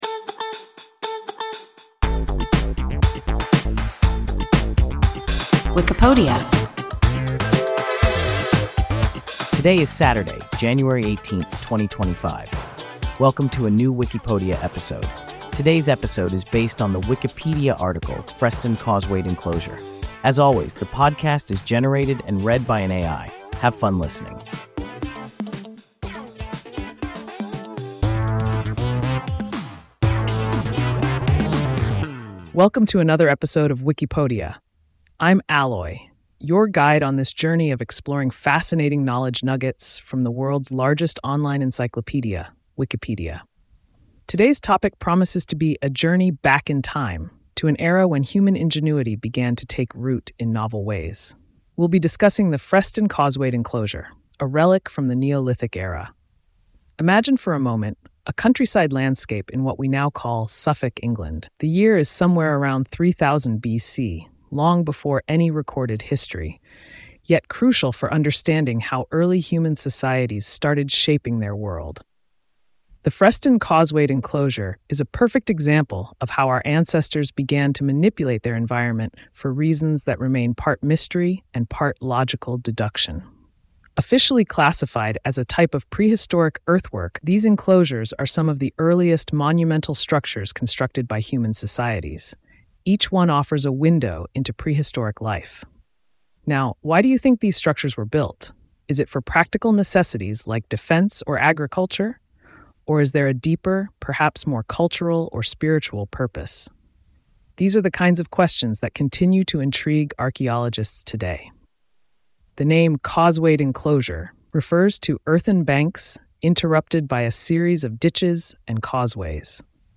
Freston causewayed enclosure – WIKIPODIA – ein KI Podcast